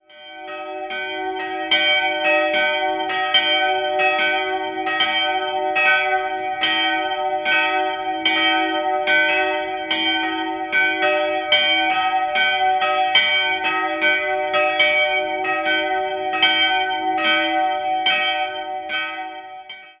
2-stimmiges Kleine-Terz-Geläute: e''-g'' Die große Glocke wurde 1909 von der Firma Oberascher in München gegossen, die kleinere ersetzt eine ehemalige Eisenhartgussglocke von Ulrich&Weule und entstand 1994 bei Albert Bachert in Heilbronn.